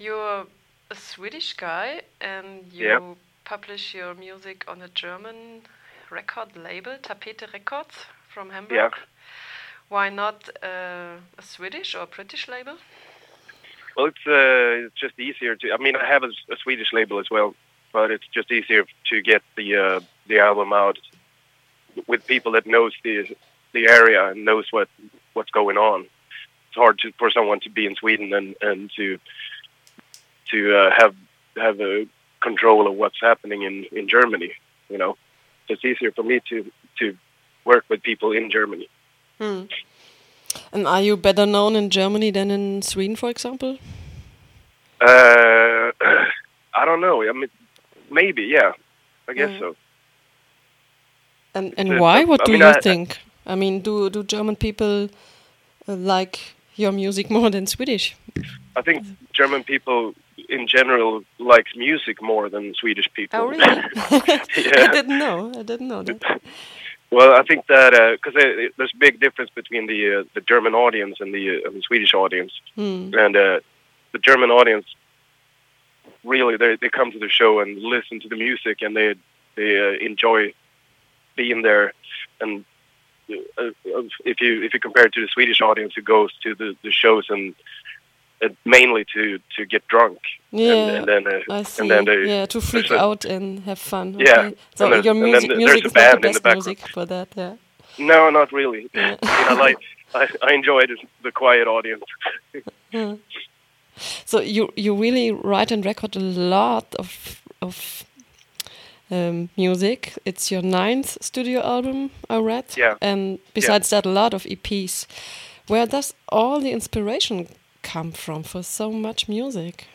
Kristofer Aström im Interview